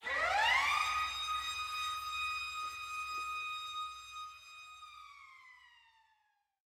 I Got The Keys Siren FX.wav